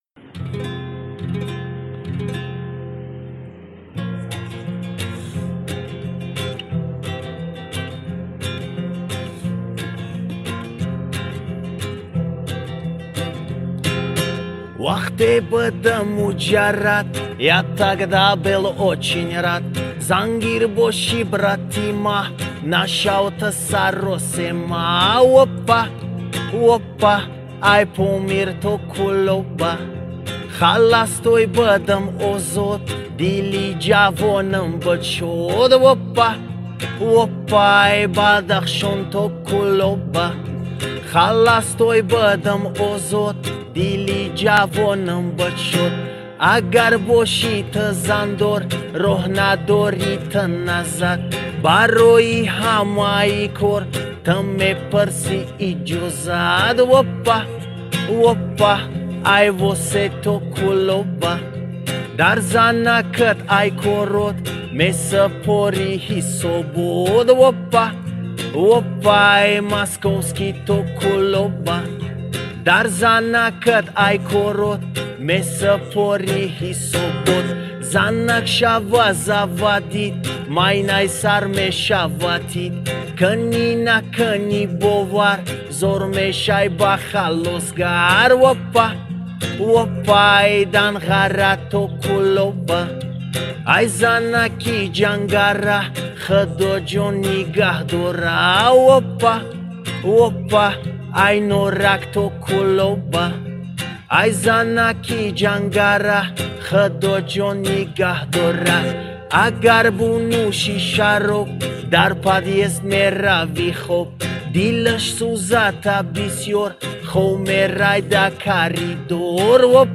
Таджикский шансон